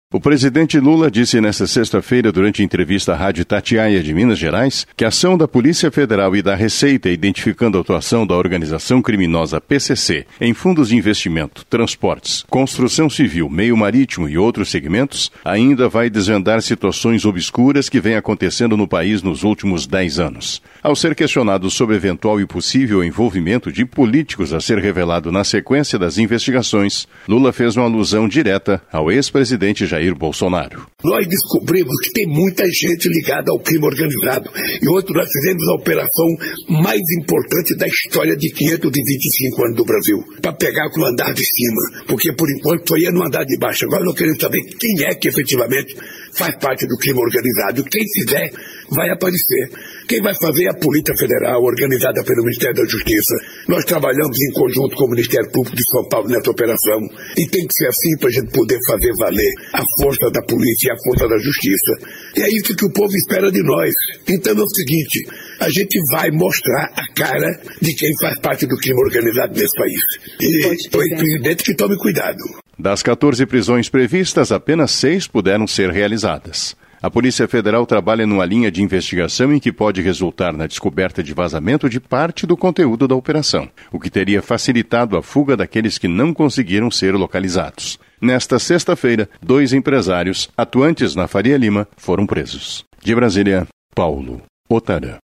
Em entrevista de rádio, Lula faz alusão a possível envolvimento de Bolsonaro ao crime organizado
Em-entrevista-de-radio-Lula-faz-alusao-a-possivel-envolvimento-de-Bolsonaro-ao-crime-organizado.mp3